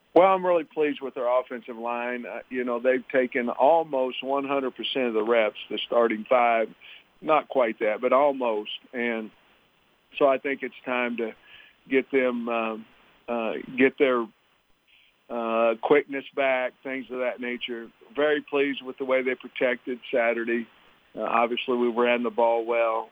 As Coach Pittman discussed in his bye week press conference, injuries are a major hindrance.
Pittman-on-O-line.wav